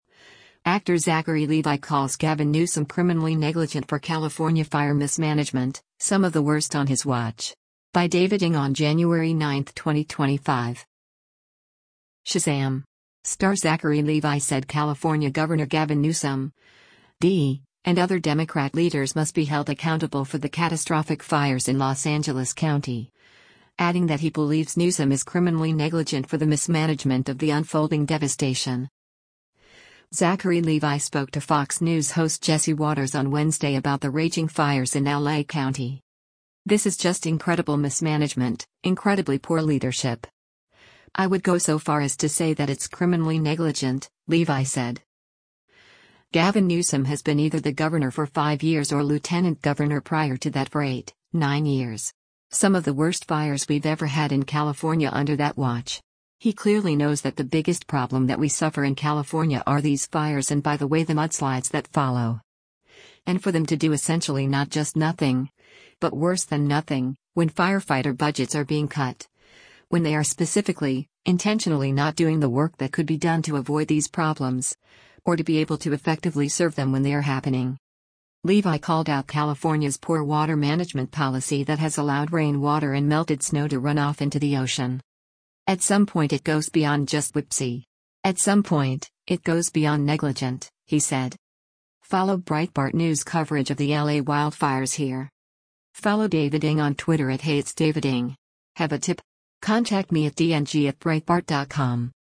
Zachary Levi spoke to Fox News host Jesse Watters on Wednesday about the raging fires in L.A. County.